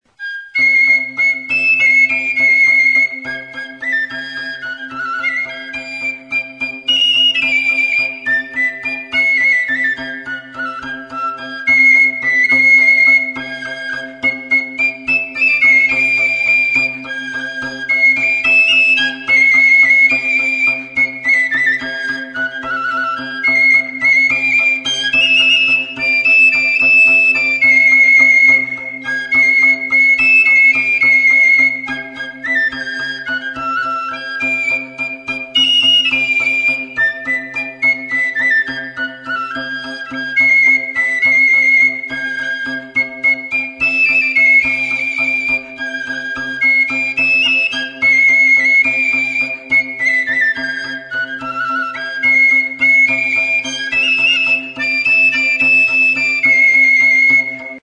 Aerófonos -> Flautas -> Recta (de una mano) + flautillas
EUROPA -> EUSKAL HERRIA
XIRULA; TXIRULA; POXPOLIN TXIKIA
DO tonuan daude.
Hiru zuloko ahokodun flauta zuzena da, bi zatitan egina.